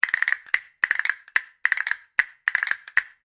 Звуки кастаньет
BPM 112 12 зациклено